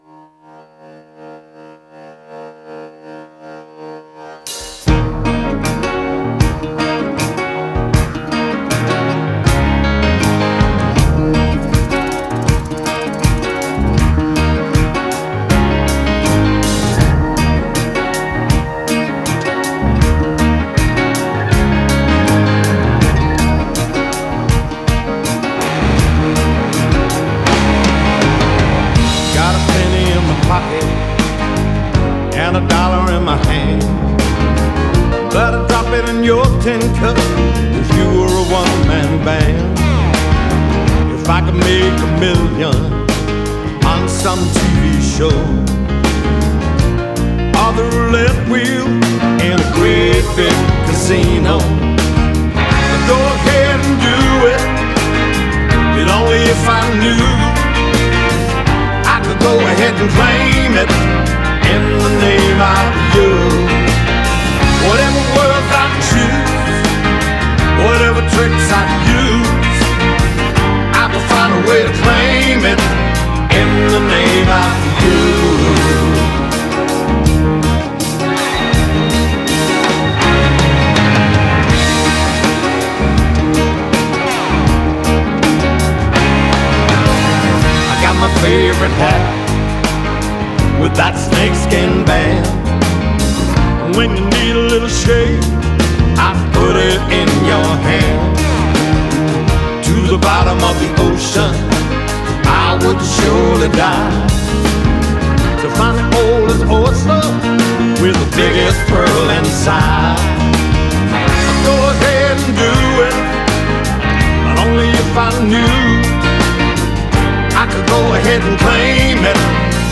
recorded at The Village in Los Angeles
Genre: Pop Rock, Classic Rock, Soft Rock